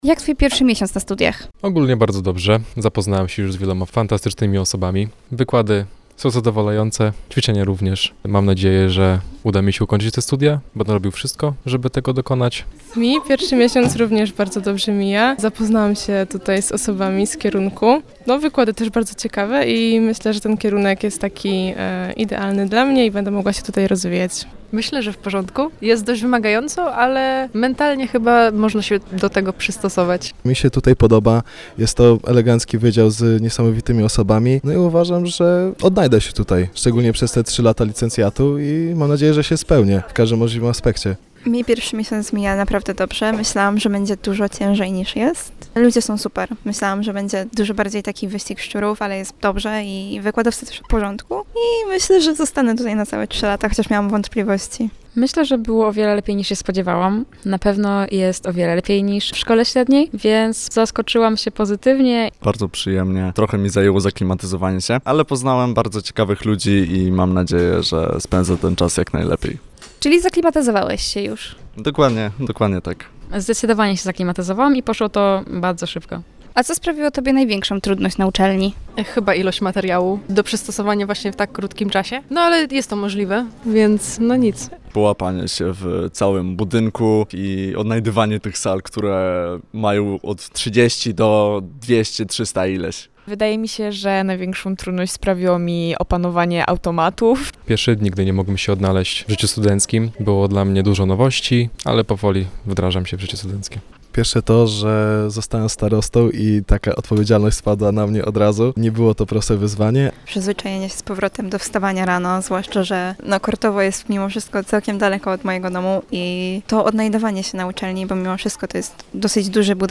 0411-MA-sonda-pierwszy-miesiac-na-studiach.mp3